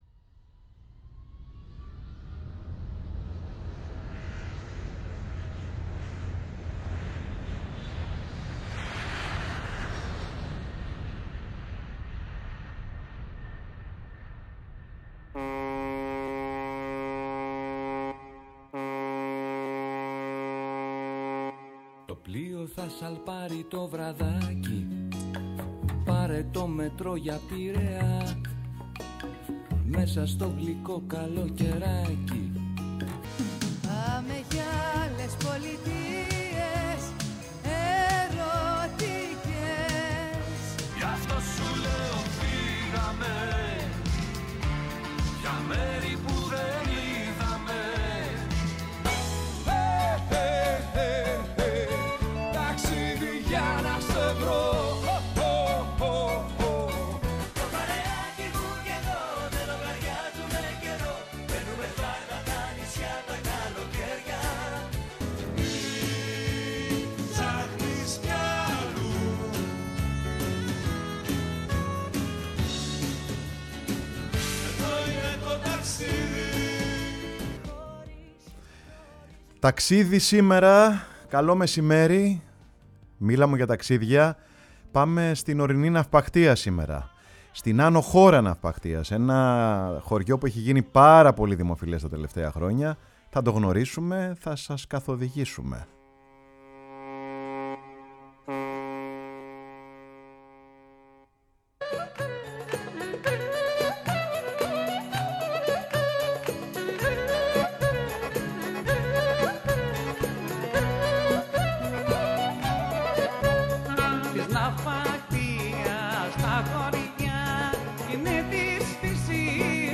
Για το χωριό και την ιστορία του, αλλά και την τοπική κουζίνα, στην εκπομπή “Μίλα μου για ταξίδια” , στη Φωνή της Ελλάδας , μίλησε ο κ. Αθανάσιος Ρέππας, πρόεδρος της δημοτικής Ενότητας Άνω Χώρας δήμου Ναυπακτίας.